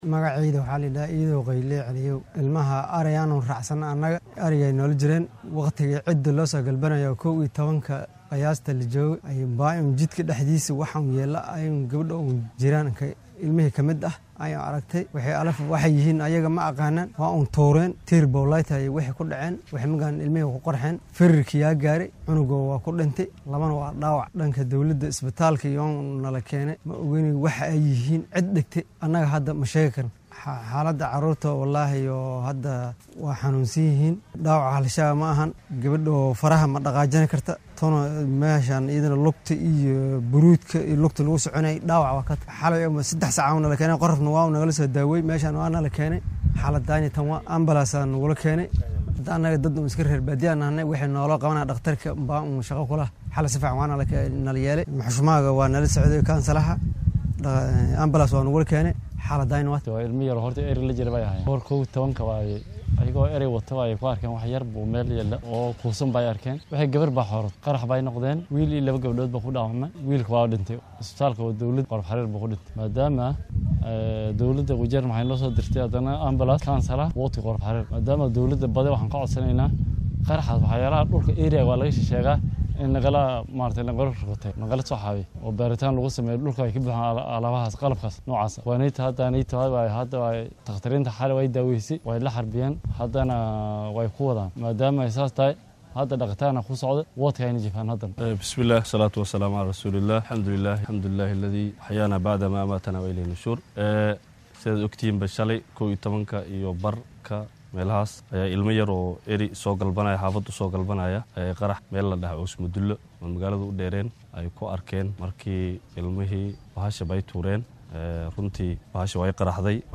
Wararka naga soo gaaraya ismaamulka Wajeer gaar ahaan tuulada Qorof Xaraar ayaa sheegaya in carruur la jirtay xoola ay ku qaraxday walxa qarxaa taasi oo keentay in hal canug ah uu dhintay halka gabadh kalena uu dhaawac soo gaaray. Qoyska carruurtaasi iyo mid ka mid ah mas’uuliyiinta deegaanka Qorof Xaraar ayaa warbaahinta Star uga warbixiyay sida ay wax u dhaceen.